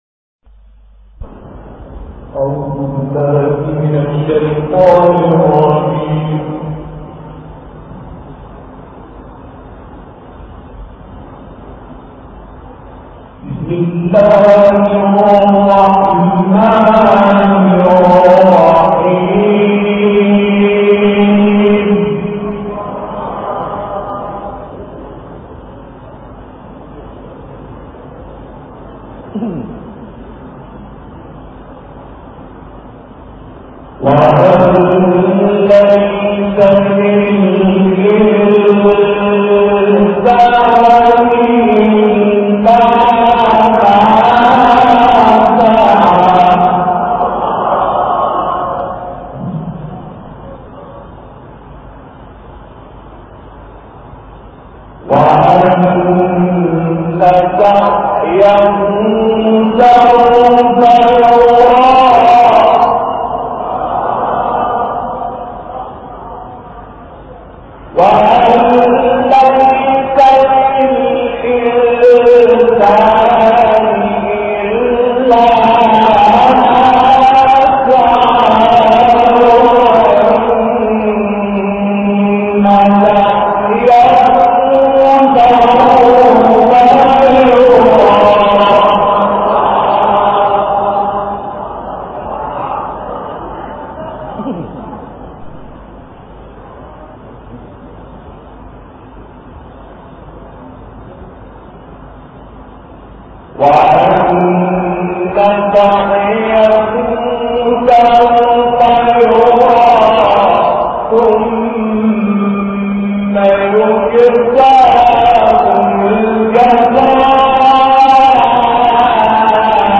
تلاوت «محمود منشاوی» و «شعبان صیاد» در رشت
گروه شبکه اجتماعی: تلاوت سوره‌هایی از قرآن کریم توسط محمود صدیق منشاوی و شعبان عبدالعزیز صیاد که در سال 1370 در شهر رشت اجرا شده است، ارائه می‌شود.
محمود صدیق منشاوی آیاتی از سوره‌های غافر، بلد، قارعه و شعبان عبدالعزیز صیاد آیاتی از سوره‌های نجم و قمر را در این برنامه تلاوت کرده‌اند.